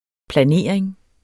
Udtale [ plaˈneˀɐ̯eŋ ]